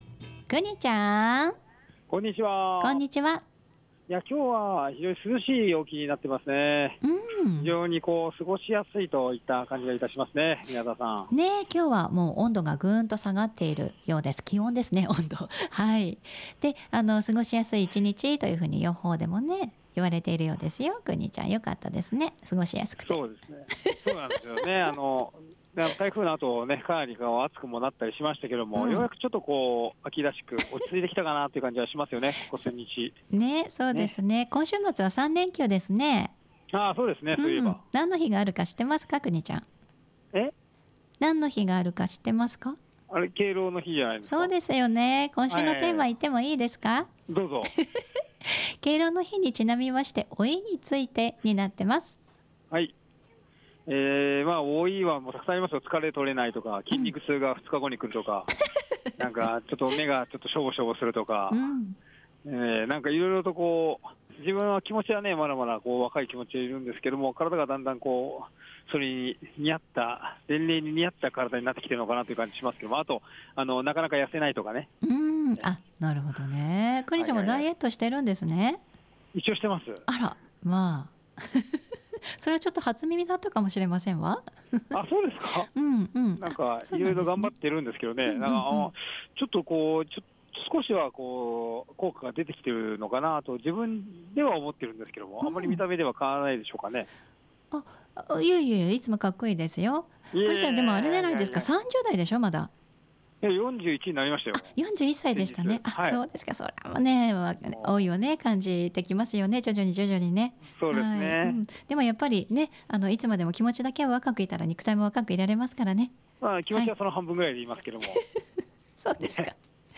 午後のカフェテラス 街角レポート
明日あさって開かれる 令和初の調布市商工まつりの会場にお邪魔しました。 今年は調布駅前広場での開催です。